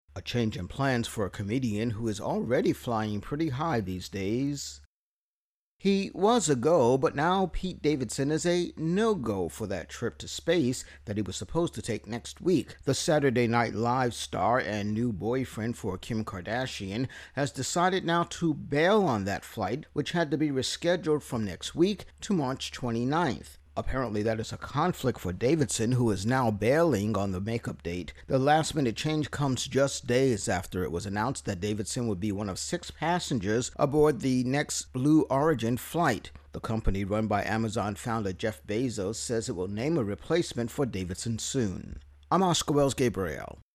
People Pete Davidson Intro and Voicer